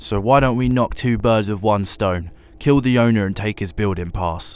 Voice Lines
Update Voice Overs for Amplification & Normalisation